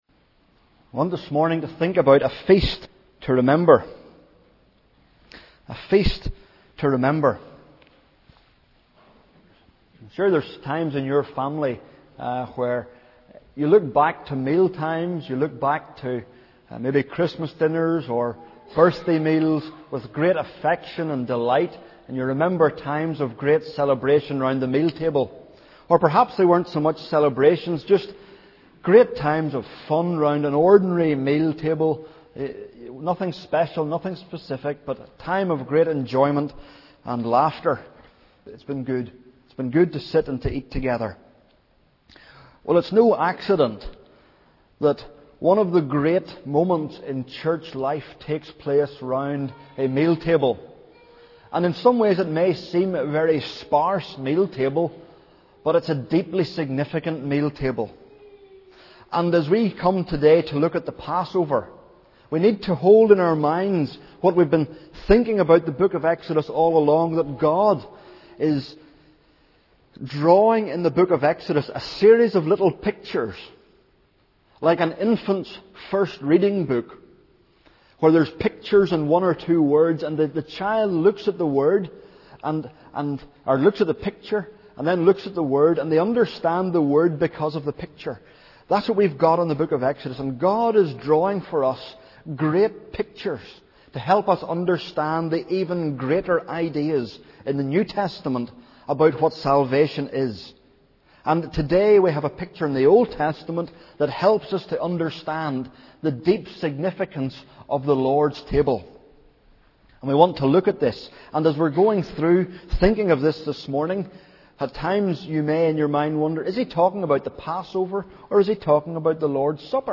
sermons:Podcast '08 A feast to remember.mp3'